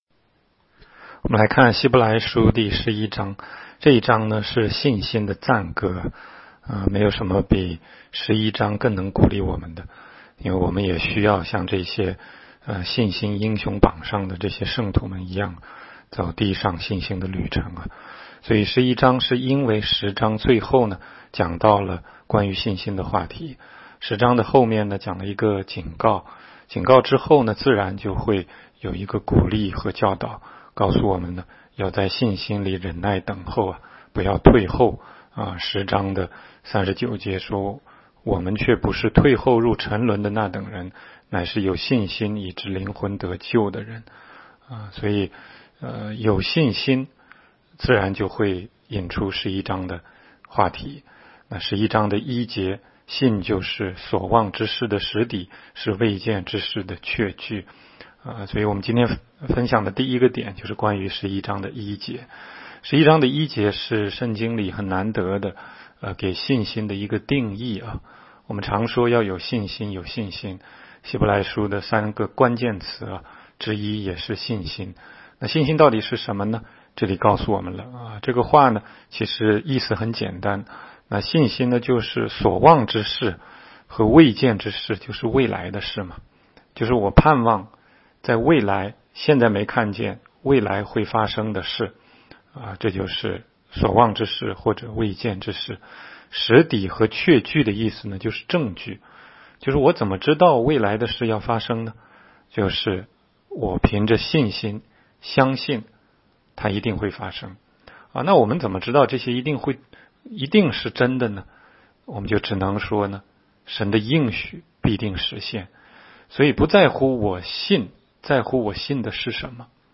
16街讲道录音 - 每日读经